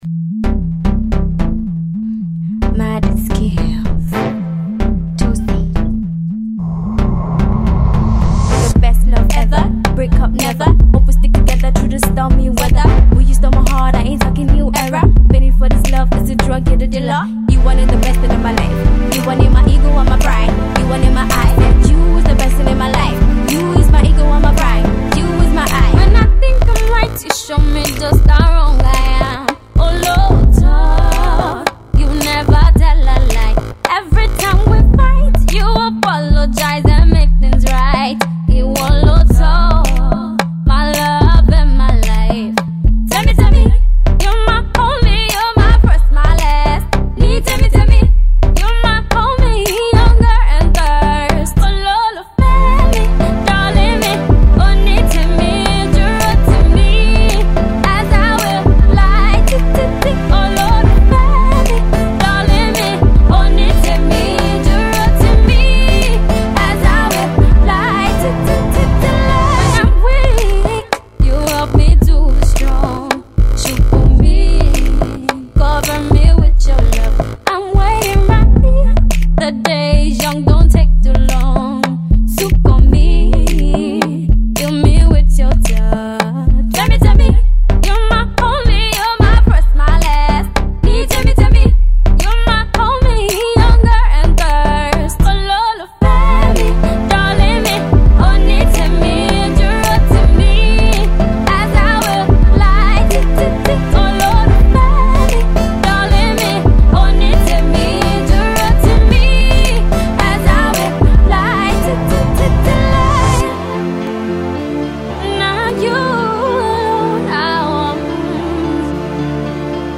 Sending some Afro Pop vibrations through your speakers
a yummy love song. She even tries a little rap.